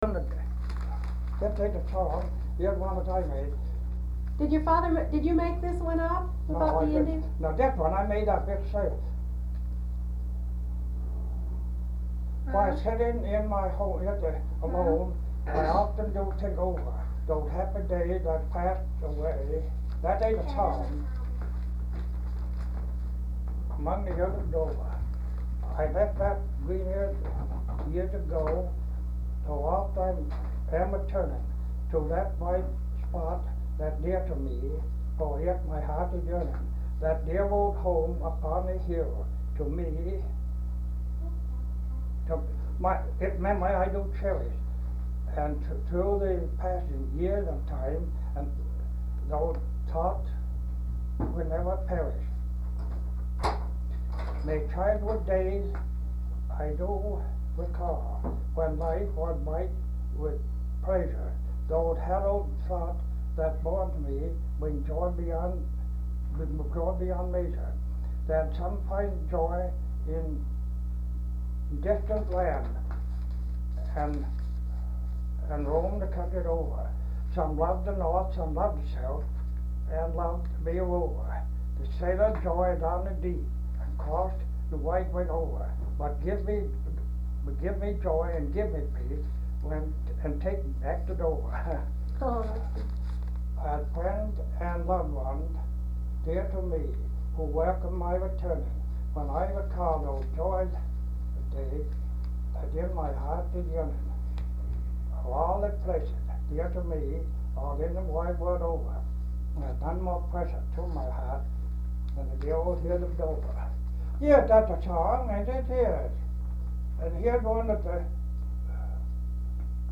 Folk songs, English--Vermont
sound tape reel (analog)
Marlboro, Vermont